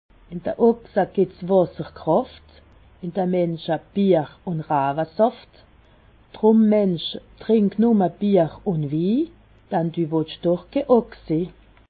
Haut Rhin
Ville Prononciation 68
Ribeauvillé